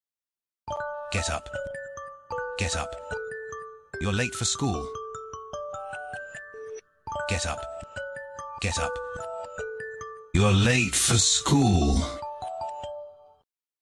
samsung alarm but its mafioso Meme Sound Effect
This sound is perfect for adding humor, surprise, or dramatic timing to your content.
samsung alarm but its mafioso.mp3